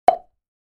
Cheek-pop-sound-effect.mp3